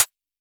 RDM_Copicat_SY1-ClHat.wav